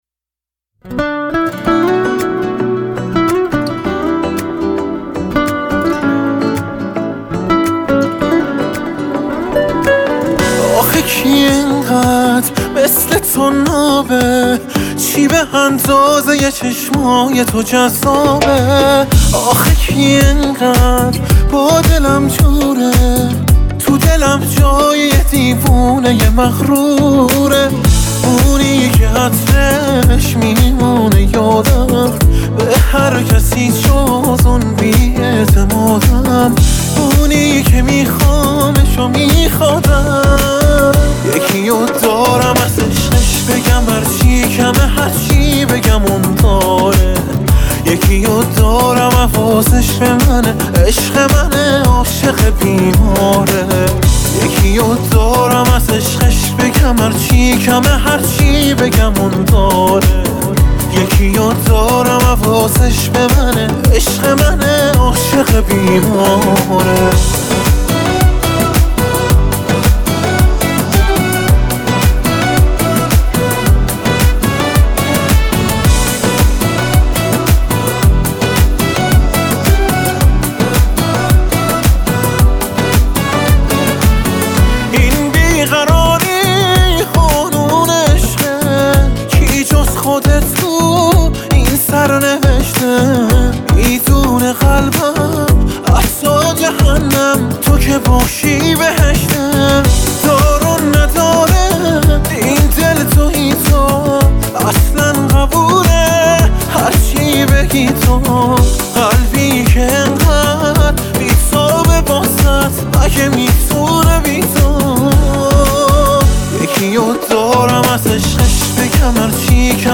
با ریتم 6/8 شاد